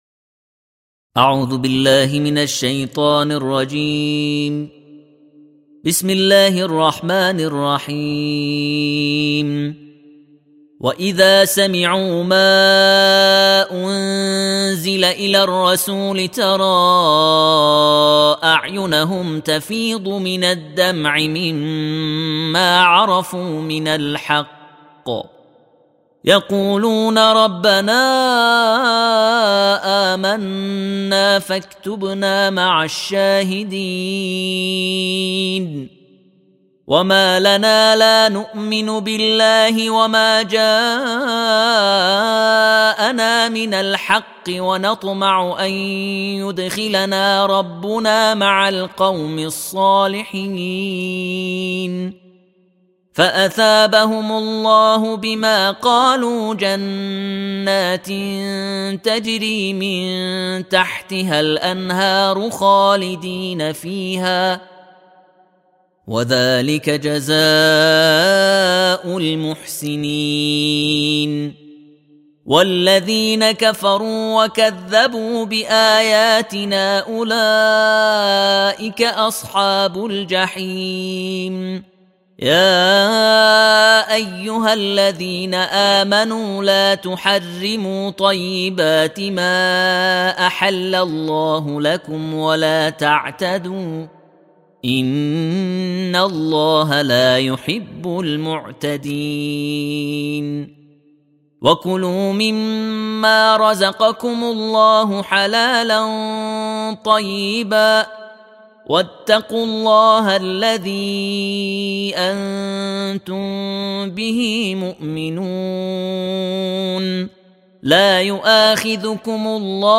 دانلود ترتیل جزء هفتم قرآن